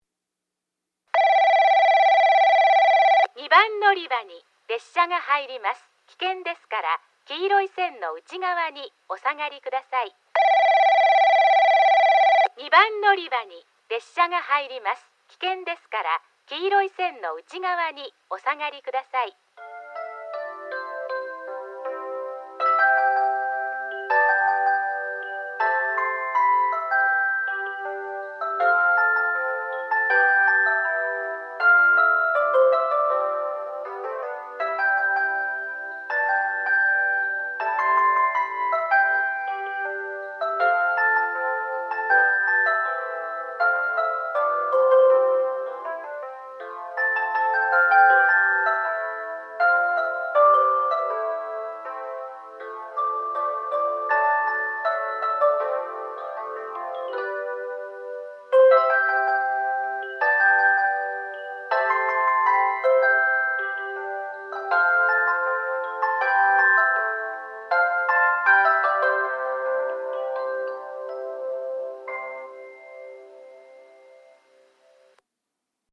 2番のりば　接近放送　女声＋接近メロディ
スピーカーはJVCラインアレイとTOAラッパ型、UNI‐PEXラッパ型です。